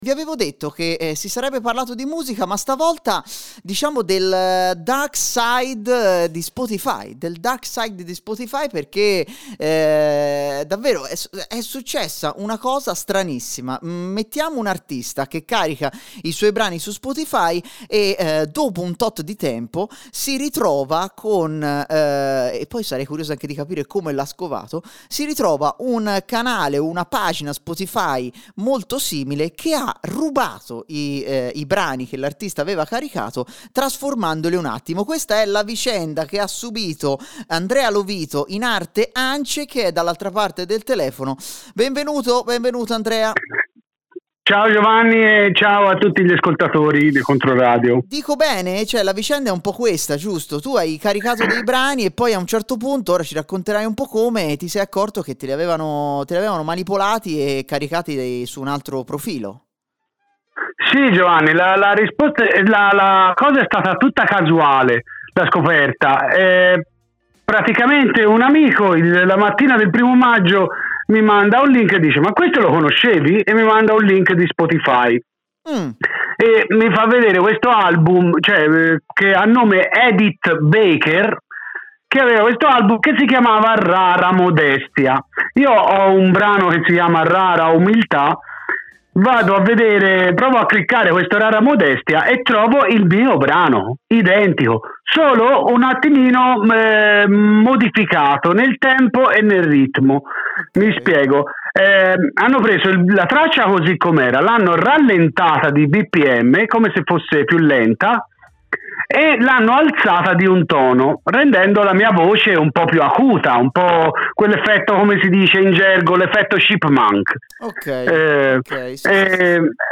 Da una scoperta casuale a un mondo oscuro nelle maglie delle più diffuse piattaforme di streaming. Ascolta l'intervista!